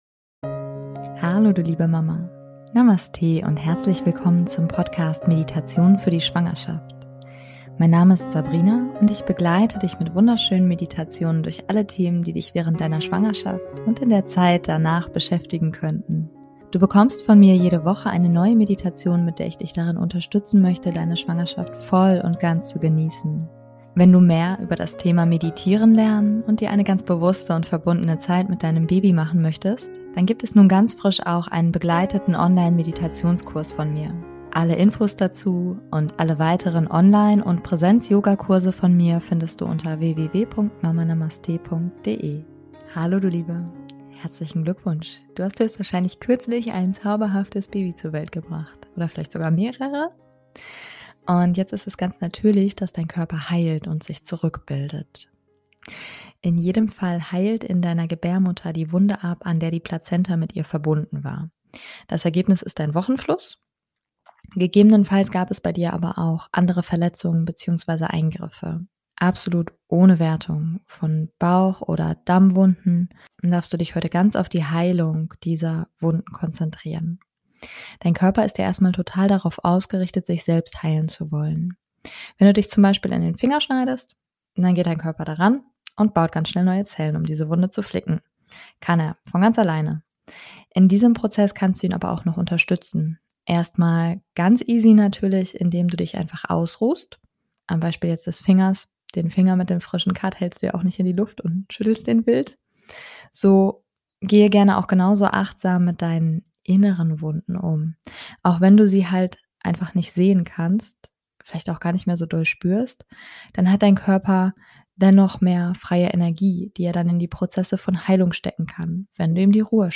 #089 - Selbstheilungskräfte nach der Geburt aktivieren - Wochenbett Meditation [Yoga Nidra] ~ Meditationen für die Schwangerschaft und Geburt - mama.namaste Podcast